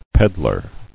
[ped·lar]